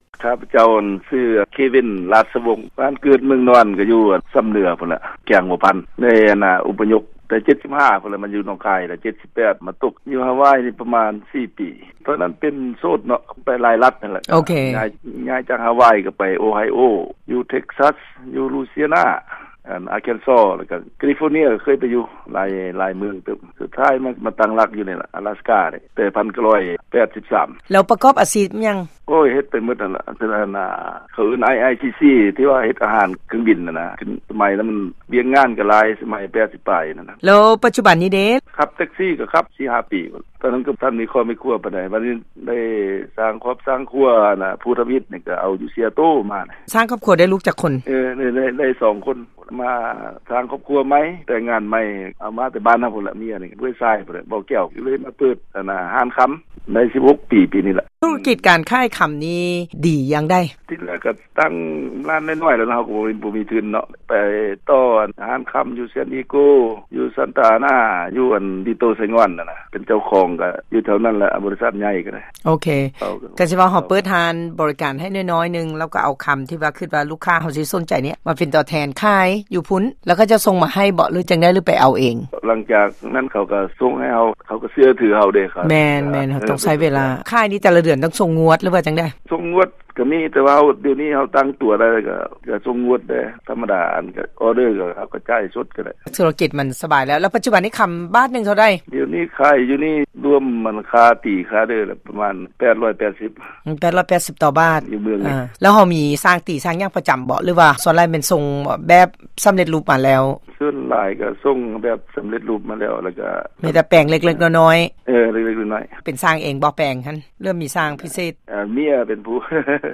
ໃນອາທິດແລ້ວນີ້ ທາງ VOA ໄດ້ໂອ້ລົມຜ່ານທາງໂທລະສັບ